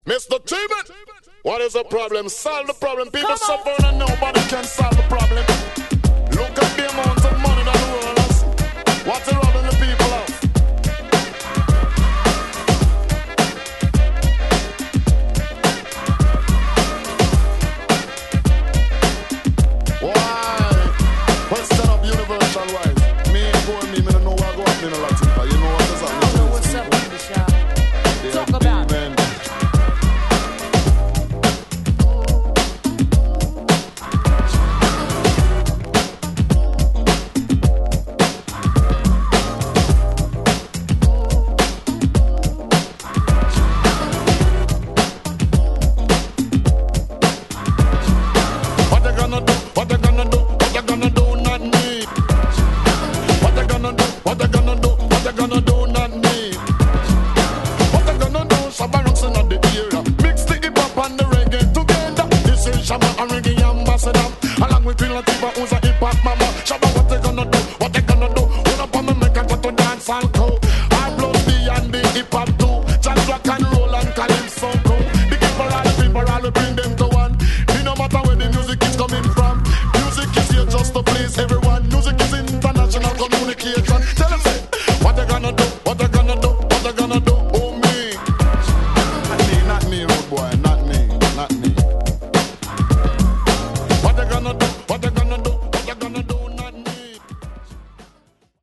・ REGGAE 12' & LP